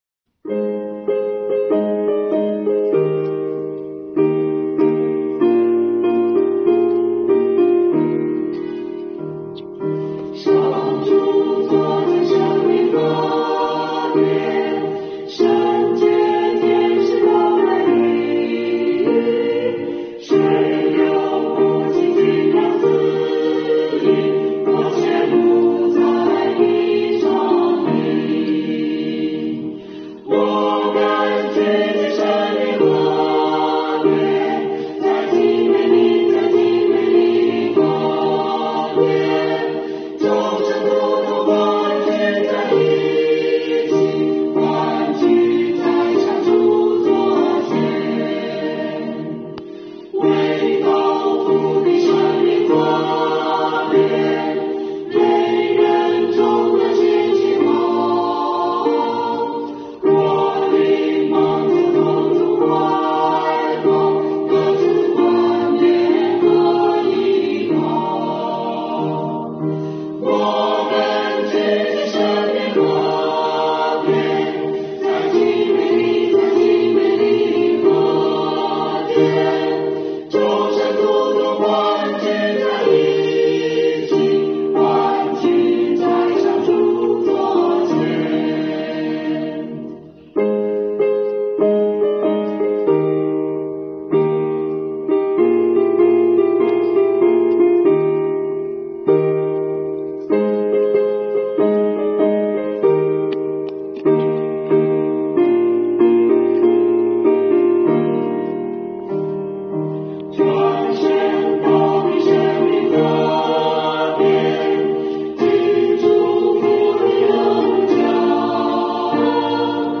赞美诗 我们聚集生命河边